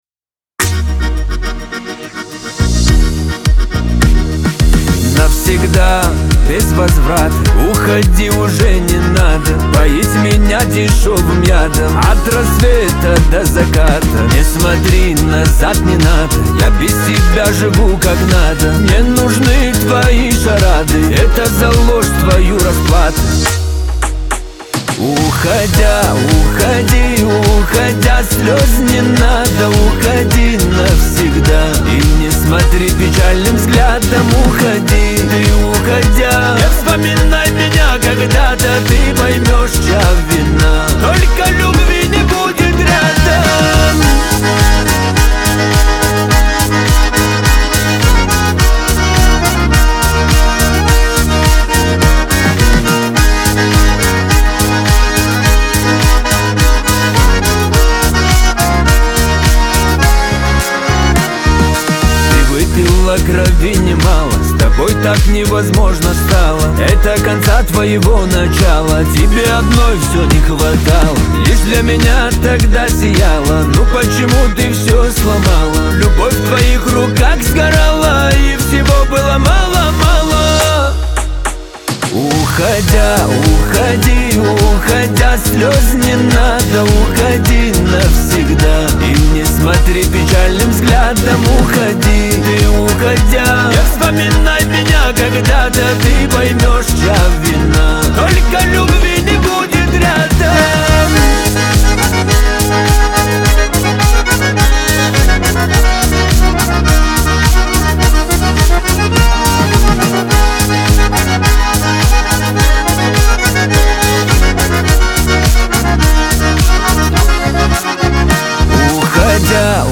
Лирика
Кавказ – поп , эстрада